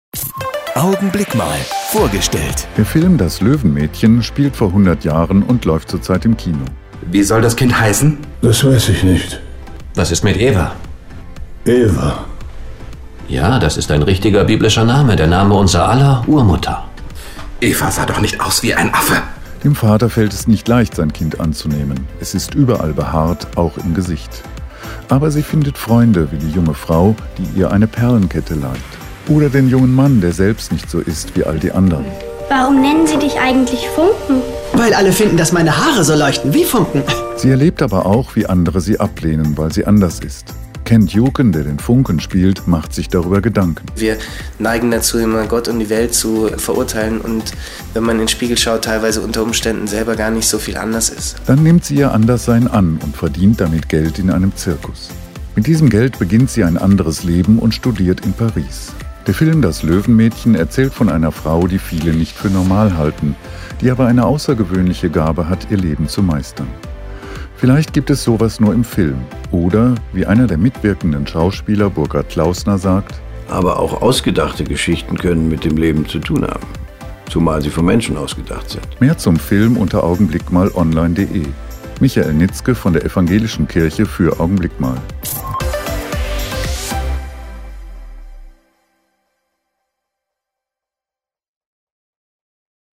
Radioandachten